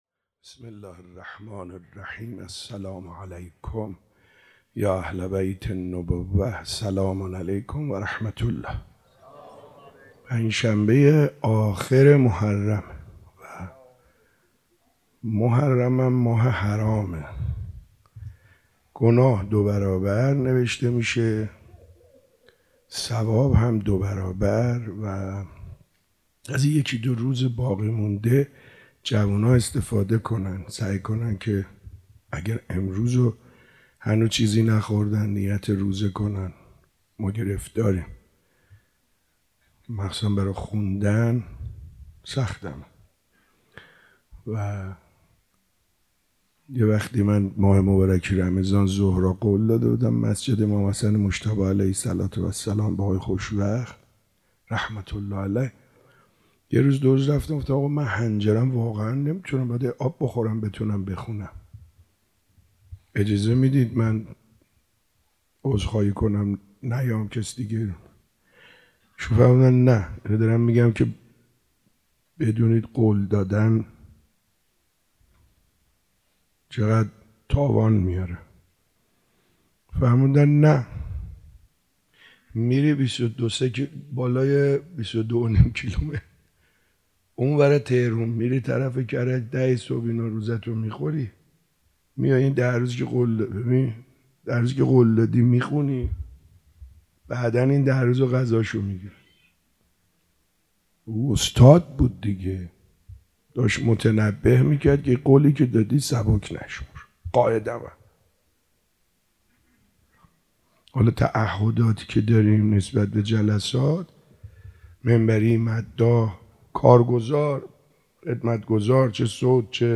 هفتگی 27 مهر 96 - صحبت